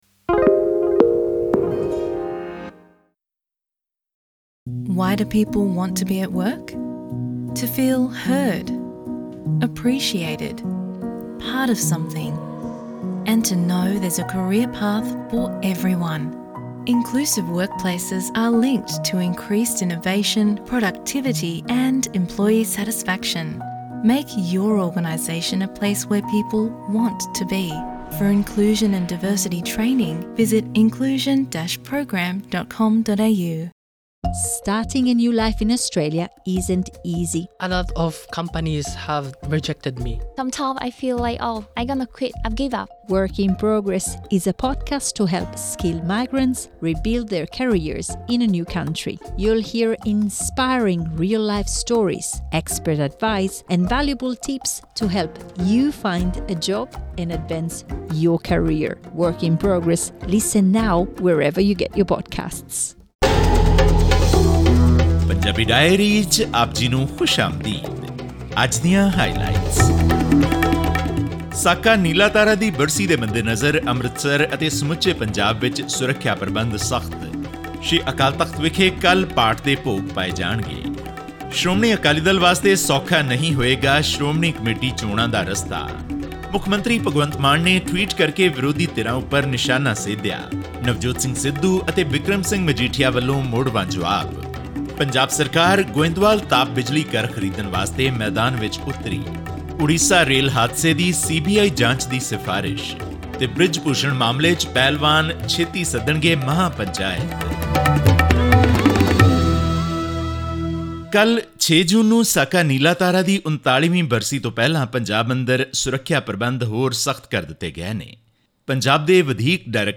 ਗੋਇੰਦਵਾਲ ਸਾਹਿਬ ਵਿਖੇ 540 ਮੈਗਾਵਾਟ ਦਾ ਪ੍ਰਾਈਵੇਟ ਥਰਮਲ ਪਾਵਰ ਪਲਾਂਟ ਅਗਲੇ ਹਫ਼ਤੇ ਨੀਲਾਮ ਹੋ ਜਾਵੇਗਾ ਕਿਓਂਕਿ ਪਲਾਂਟ ਦਾ ਸੰਚਾਲਨ ਕਰਨ ਵਾਲੀ ਪ੍ਰਾਈਵੇਟ ਕੰਪਨੀ, ਜੀਵੀਕੇ ਪਾਵਰ ਕਾਰਪੋਰੇਟ ਦੀਵਾਲੀਆ ਹੋ ਗਈ ਹੈ। ਇਸ ਪੂਰੀ ਪ੍ਰਕਿਰਿਆ ਦਾ ਇਸ ਪਲਾਂਟ ਤੋਂ ਬਿਜਲੀ ਦੀ ਉਪਲਬਧਤਾ 'ਤੇ ਕੋਈ ਅਸਰ ਨਹੀਂ ਪਵੇਗਾ ਕਿਓਂਕਿ ਜੋ ਵੀ ਕੰਪਨੀ ਇਹ ਬੋਲੀ ਜਿੱਤੇਗੀ ਉਹ ਪੀਐਸਪੀਸੀਐਲ (PSPCL) ਨੂੰ ਬਿਜਲੀ ਸਪਲਾਈ ਕਰਨਾ ਜਾਰੀ ਰੱਖੇਗੀ। ਇਸ ਖ਼ਬਰ ਅਤੇ ਪੰਜਾਬ ਨਾਲ ਸਬੰਧਤ ਹੋਰ ਖ਼ਬਰਾਂ ਦੇ ਵੇਰਵੇ ਜਾਣਨ ਲਈ ਸੁਣੋ ਪੰਜਾਬੀ ਡਾਇਰੀ ਦੀ ਰਿਪੋਰਟ।